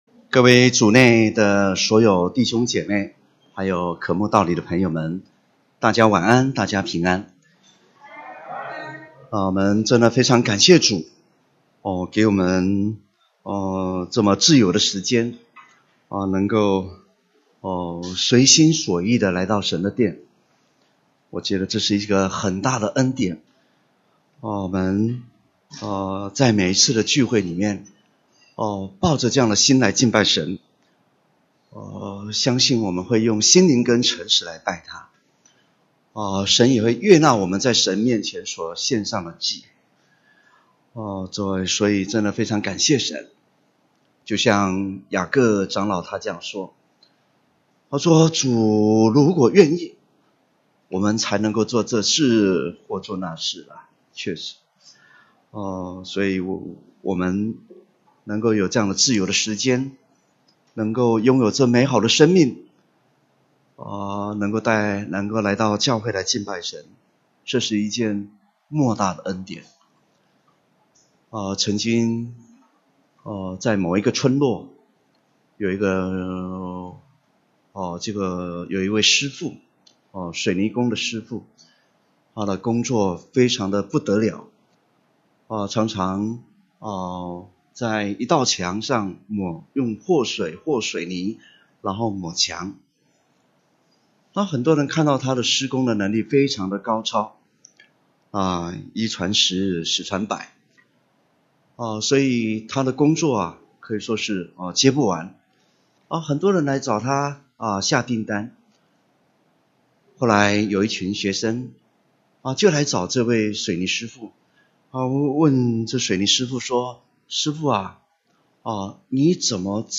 2018年7月份講道錄音已全部上線